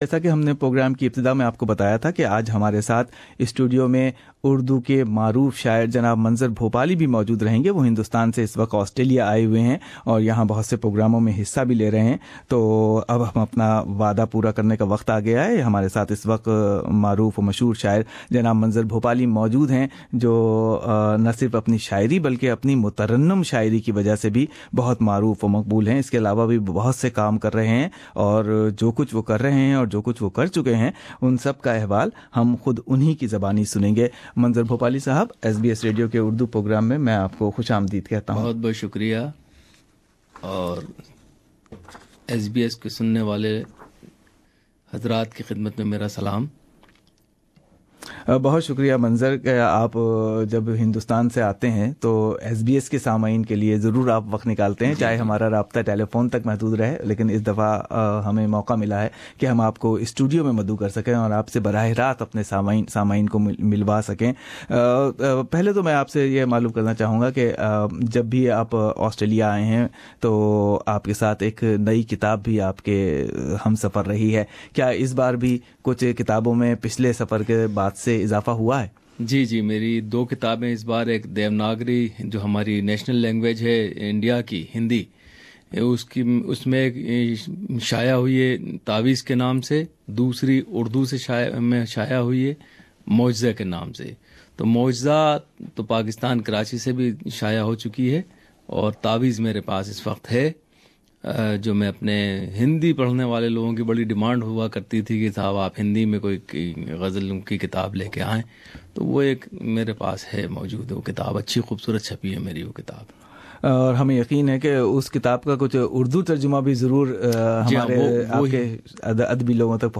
Manzar Bhopali is currently visiting Australia and we interviewed him to know about his literary career that is spanning over four decades. Manzar has recited melodious poems in thousands of poetry congressional gatherings (Mushairas) in five continents and more than 30 countries.